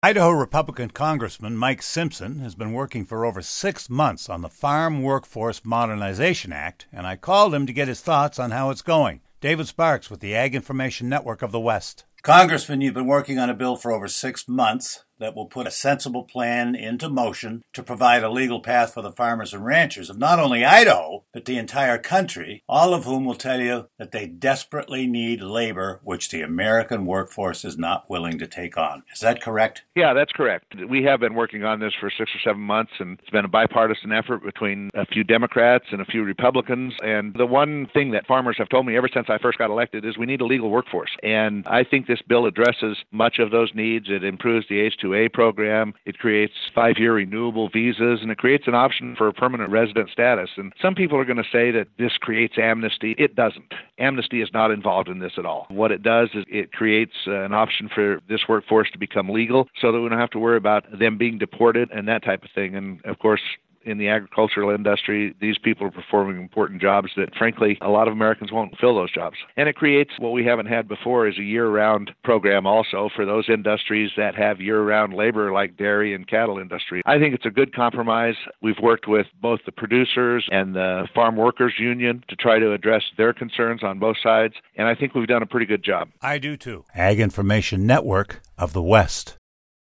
Idaho Republican Congressman Mike Simpson has been working for over six months on the Farm Workforce Modernization Act and I called him to get his thoughts on how it's going.